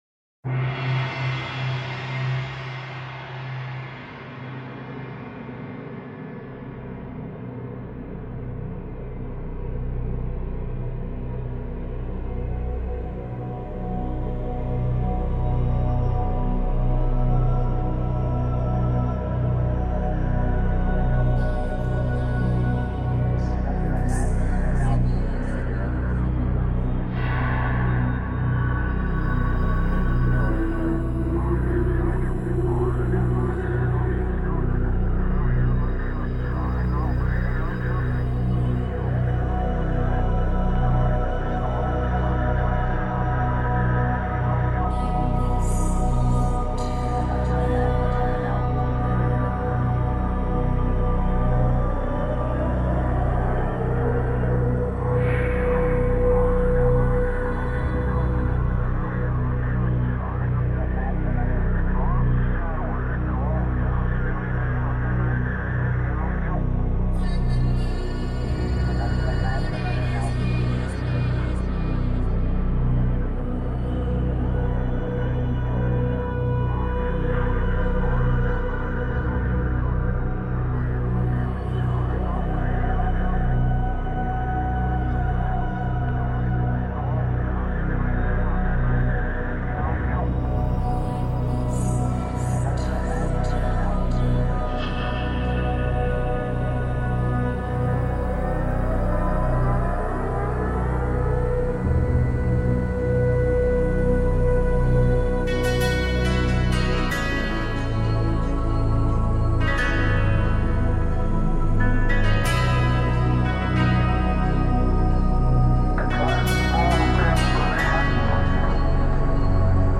古典音乐
揉合了流行唱腔与正统声乐精粹所在，而且睛深款款。
电子合成器与真人乐团